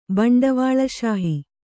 ♪ baṇḍavāḷa śahi